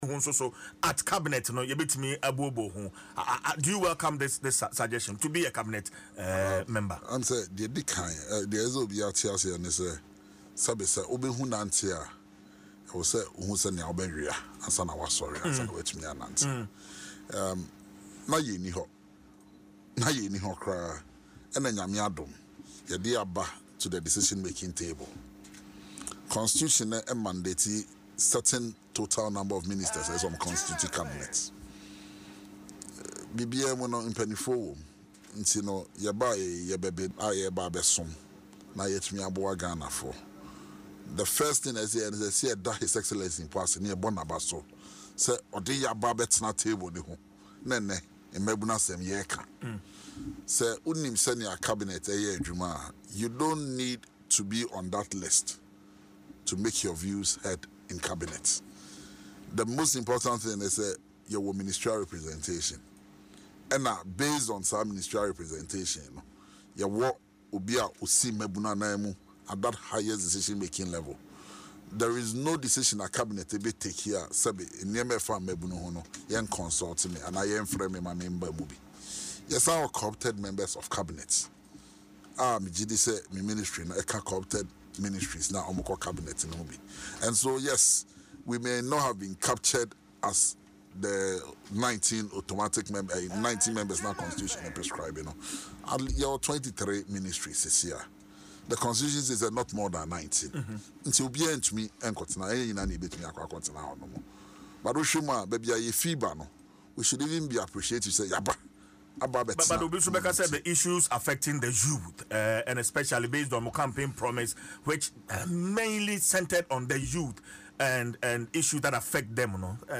Speaking on Adom FM’s morning show Dwaso Nsem, Mr. Opare-Addo stated that while the Youth Ministry may not be directly part of the 19-member Cabinet, it is still well-represented in key decision-making processes.